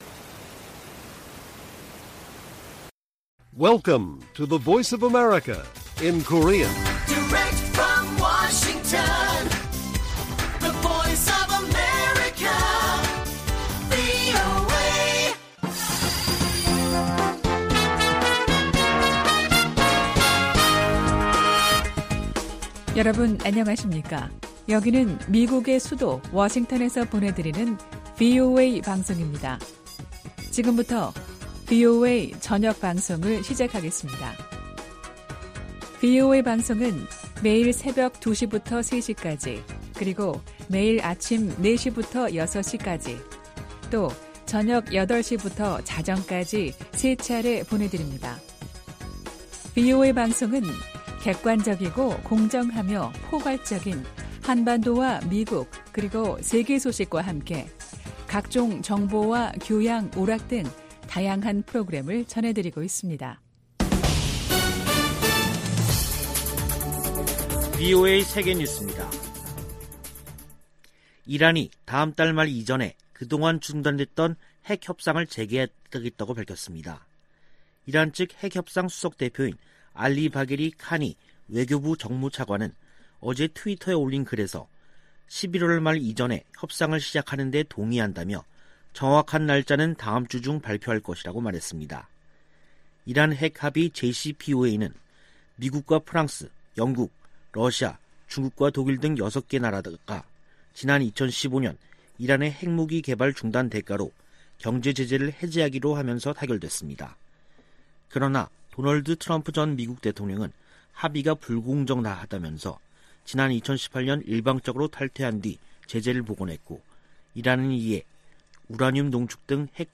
VOA 한국어 간판 뉴스 프로그램 '뉴스 투데이', 2021년 10월 28일 1부 방송입니다. 북한이 종전선언 논의를 위한 선결 조건으로 미-한 연합훈련 중단을 요구하고 있다고 한국 국가정보원이 밝혔습니다. 마크 밀리 미 합참의장은 북한이 미사일 등으로 도발하고 있다며, 면밀히 주시하고 있다고 밝혔습니다. 토니 블링컨 미 국무장관이 보건과 사이버 안보 등에 전문성을 갖추고 다자외교를 강화하는 미국 외교 미래 구상을 밝혔습니다.